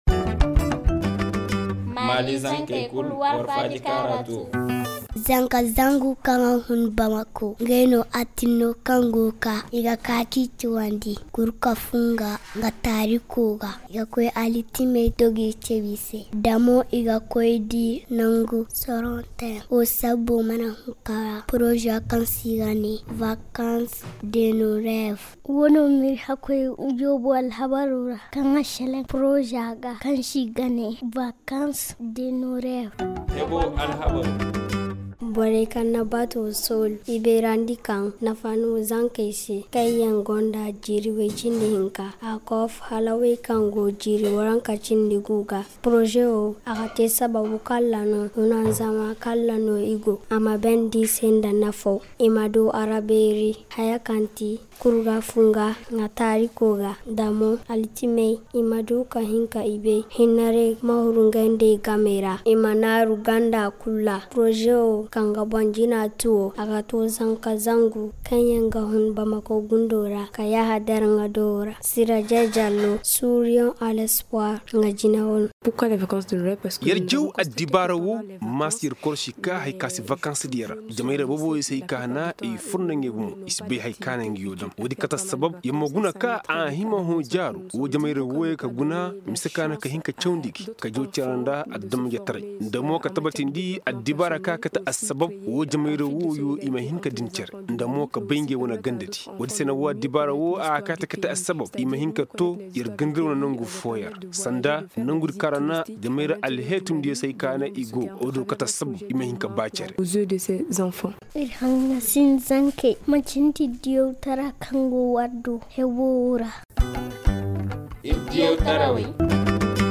Magazine en français: Télécharger